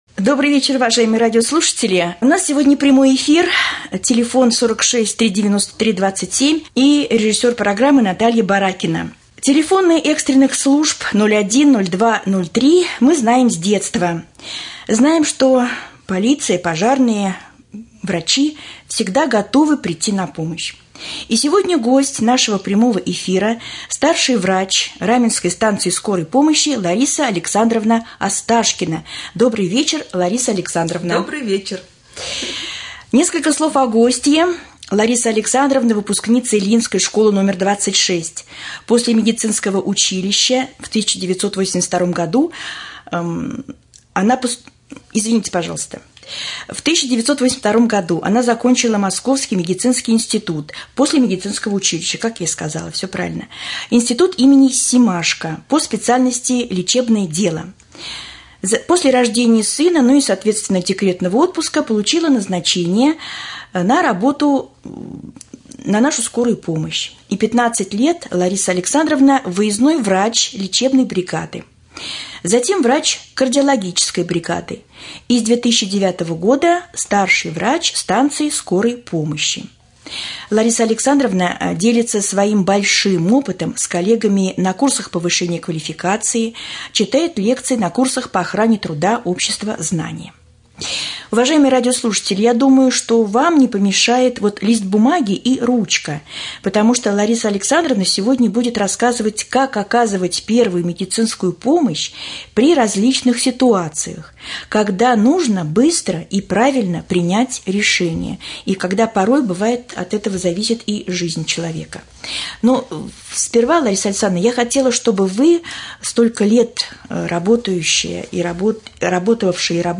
28.04.2015 г. в эфире Раменского радио